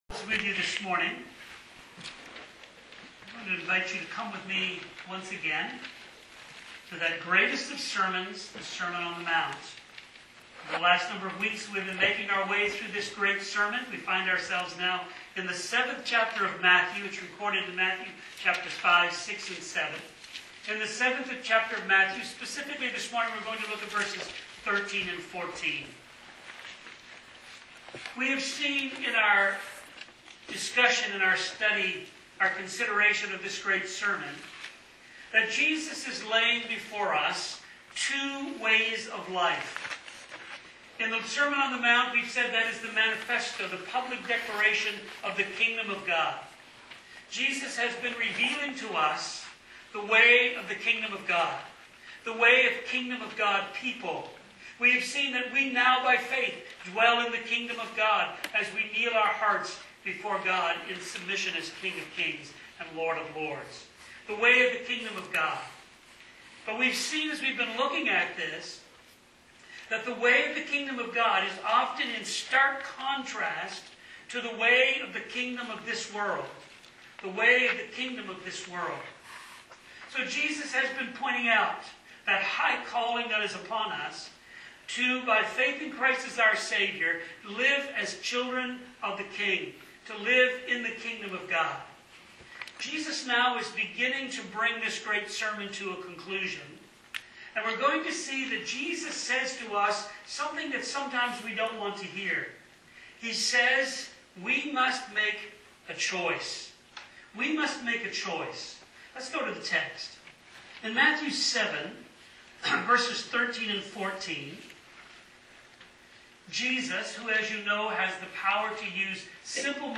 A Clear Choice Matthew 7:13-14 Sermon on the Mount Series | Burnaby North Baptist Church